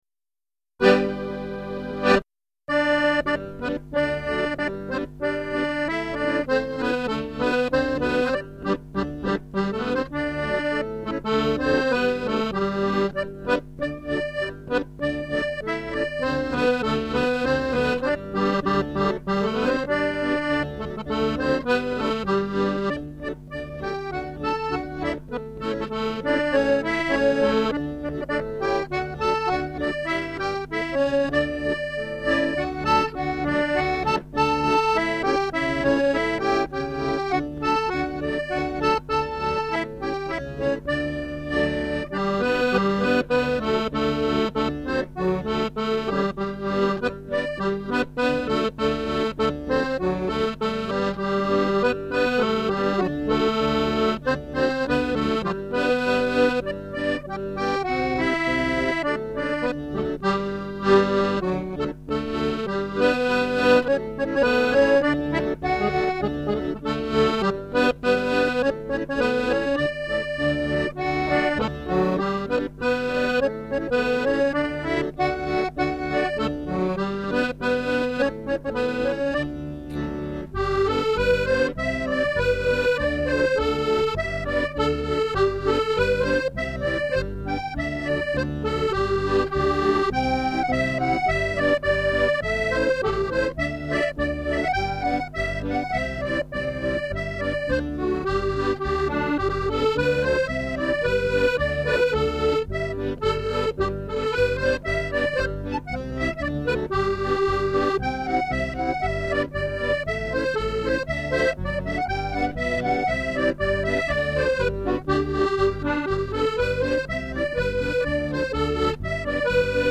Music - 16 bar polkas